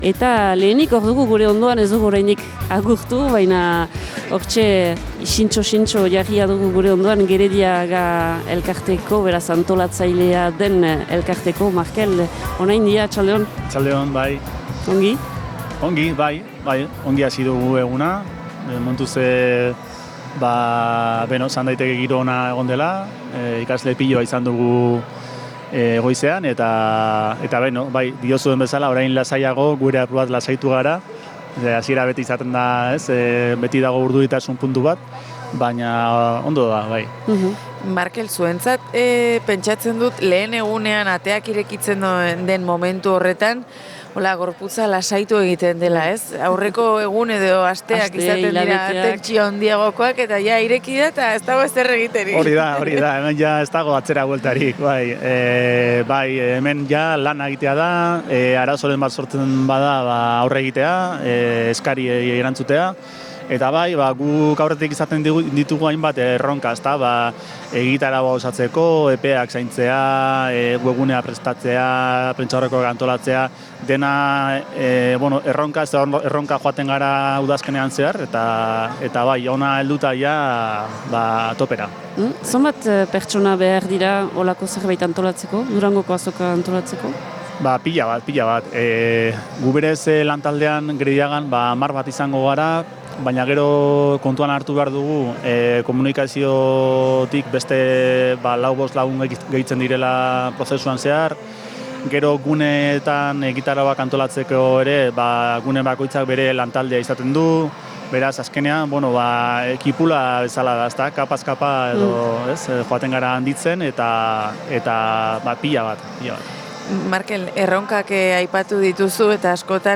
Gaurkoan Durangoko Azokatik Zebrabidea saio berezia izan dugu.